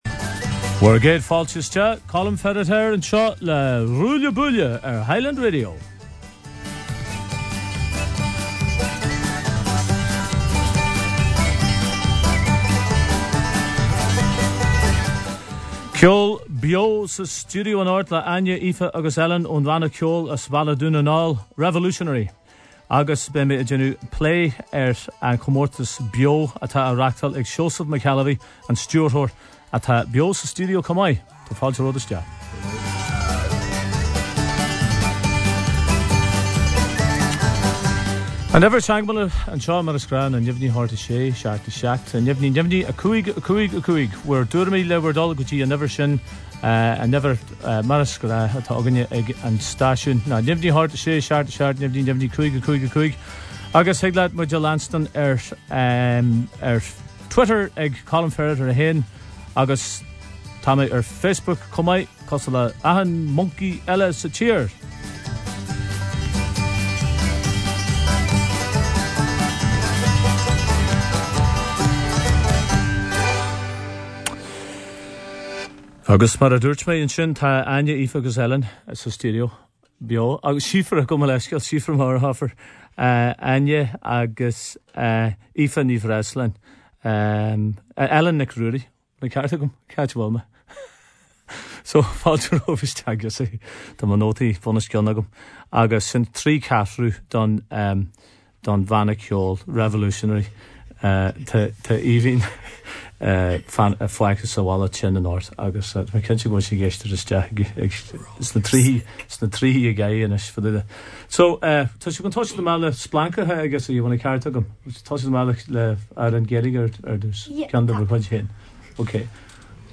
ceol ó ‘Revolutionary’ beo sa stiúideo.